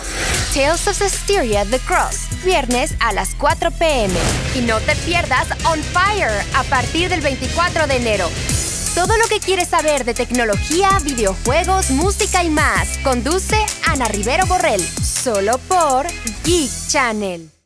Juvenil
Dulce
Sensual